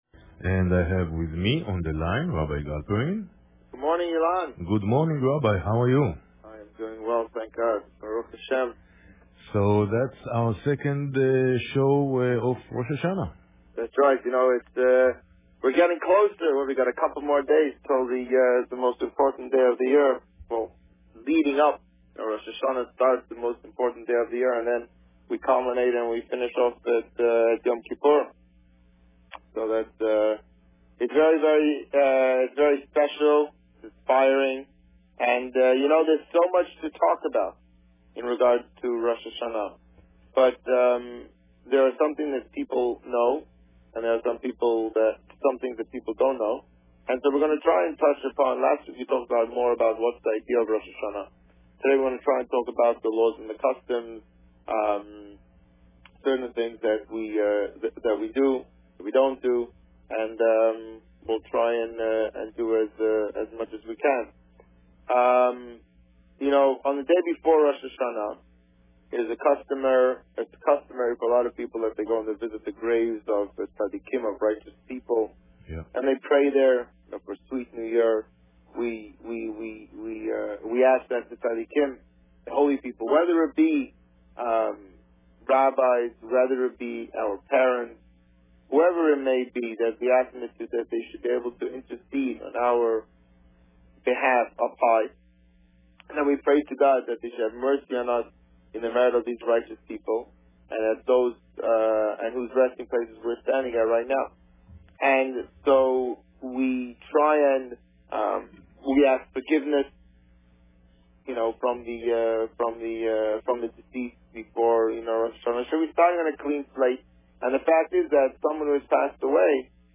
The Rabbi on Radio
Today, the Rabbi continued to speak about various topics related to the coming Rosh Hashanah holiday. Listen to the interview here.